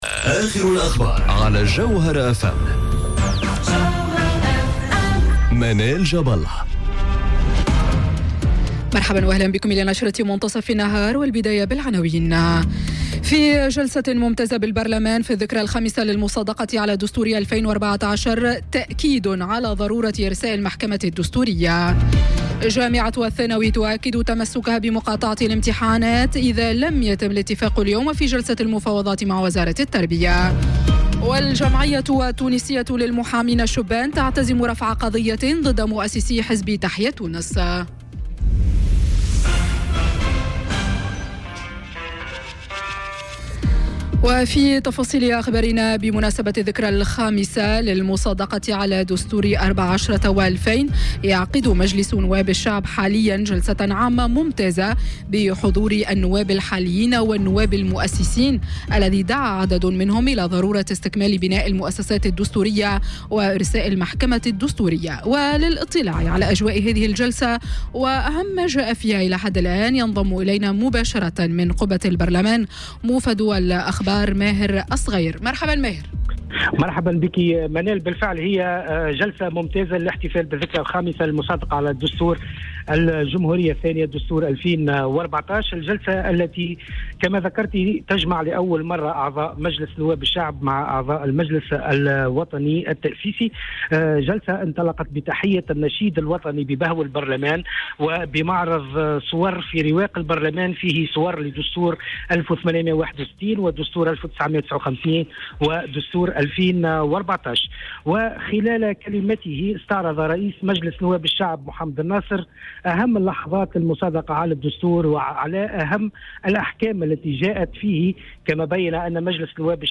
نشرة أخبار منتصف النهار ليوم الإثنين 28 جانفي 2019